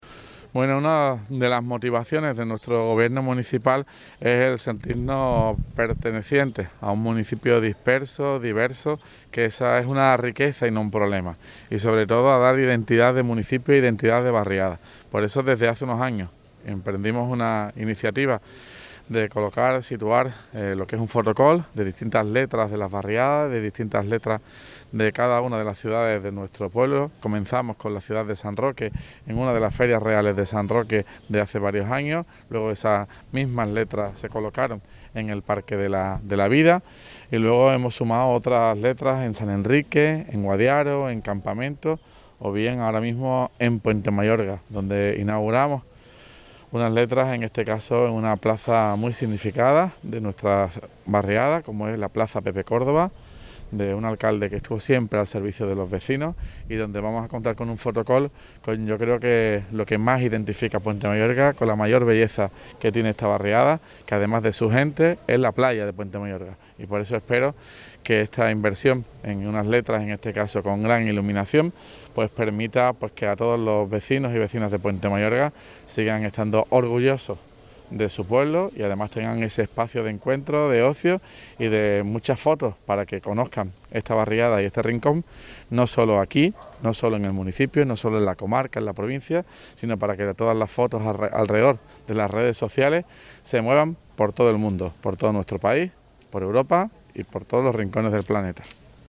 TOTAL ALCALDE LUCES PUENTE .mp3